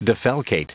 Prononciation du mot defalcate en anglais (fichier audio)
Prononciation du mot : defalcate